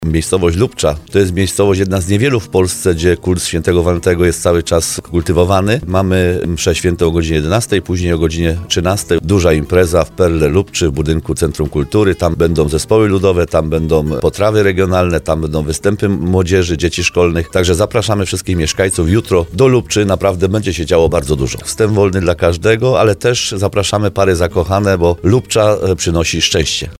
Jak mówi burmistrz Ryglic Paweł Augustyn, kult św. Walentego jest wciąż pielęgnowany w Lubczy i okolicach: